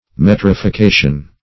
Metrification \Met`ri*fi*ca"tion\, n.